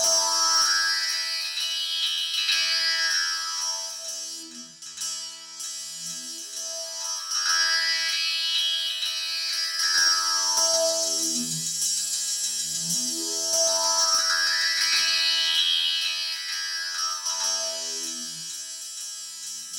ElectricBerimbau3_97_E.wav